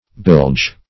Bilge \Bilge\ (b[i^]lj), v. i. [imp. & p. p. Bilged